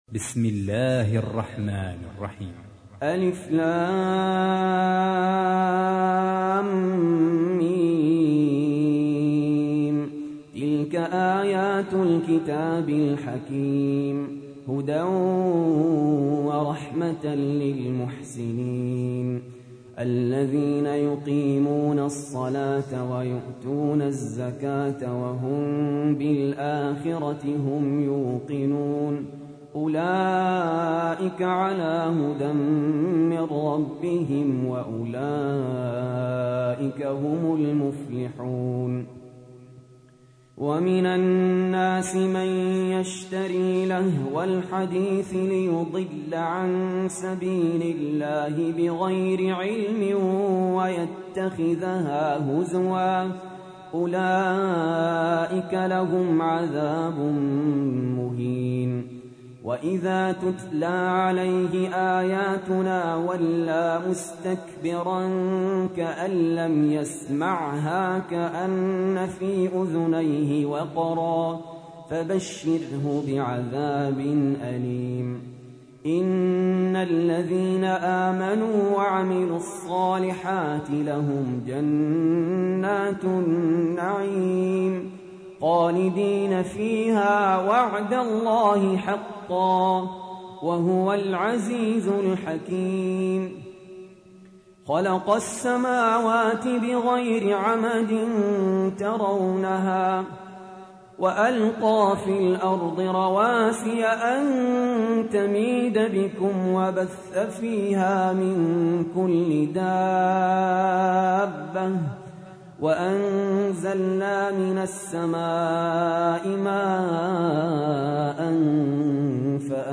تحميل : 31. سورة لقمان / القارئ سهل ياسين / القرآن الكريم / موقع يا حسين